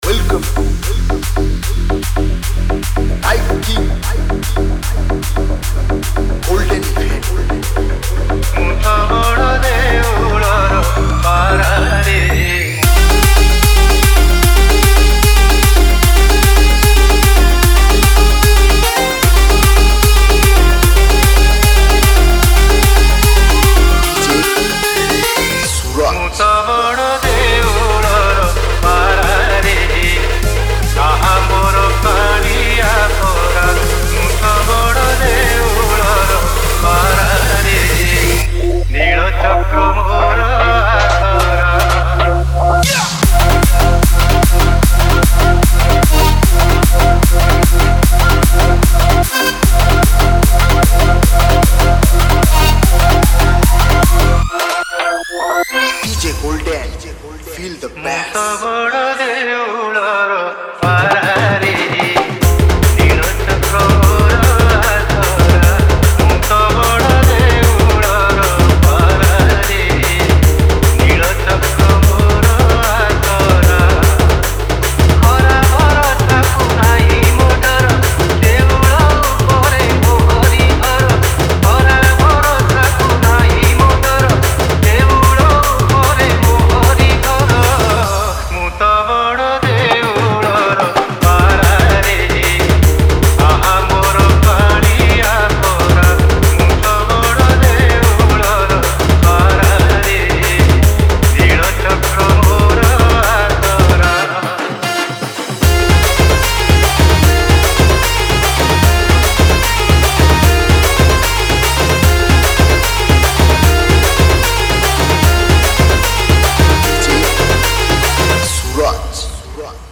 Bhajan Dj Song Collection 2022 Songs Download